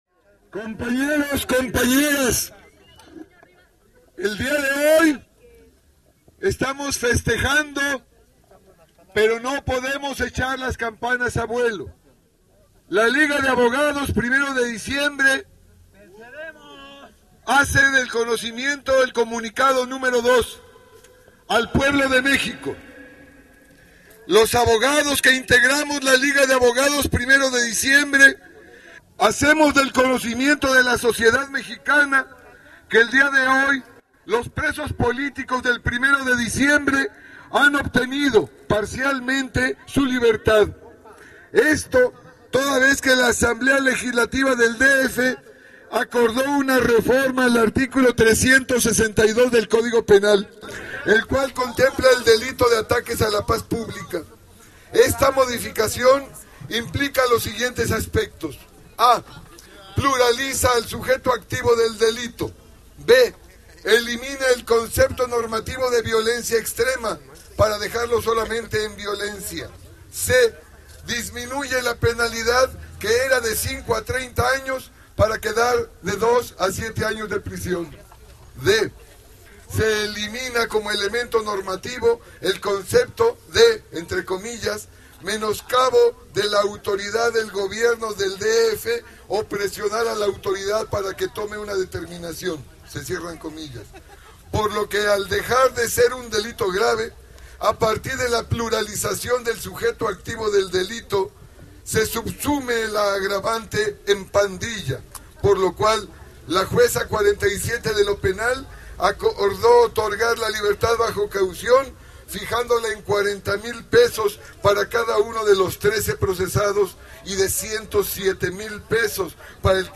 Minutos más tarde dio inicio un acto donde “La Liga de Abogados 1 de Diciembre” y los ahora ex Presos Políticos, darían sus respectivos pronunciamientos. La Liga de Abogados enfatizo que la reforma hecha por la Asamblea Legislativa del DF, al modificar el artículo 362 del código Penal del DF, solo es un pequeño paso en camino de la verdadera justicia.
Pronunciamiento de la Liga de Abogados 1 de Diciembre